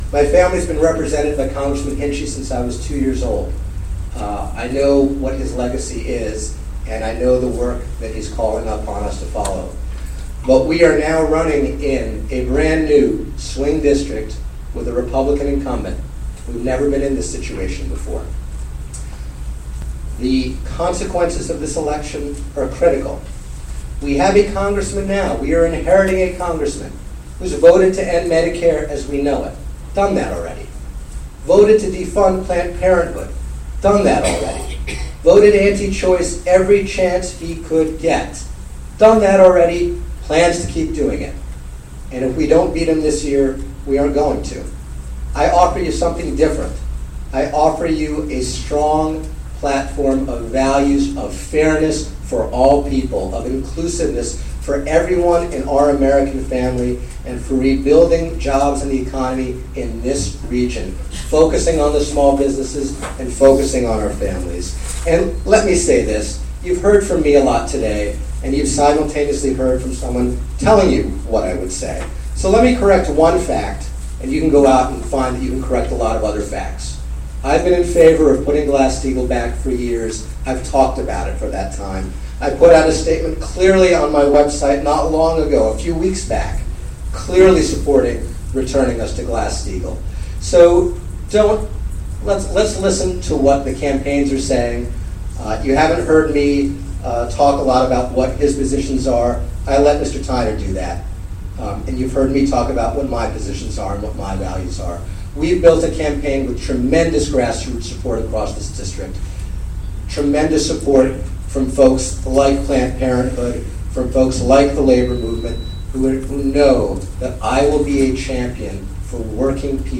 Live Congressional Debate
From livestream from The Daily Freeman newspaper aired on WGXC 90.7-FM. 2:24 Held at 12:30 p.m. at Woodland Pond retirement community.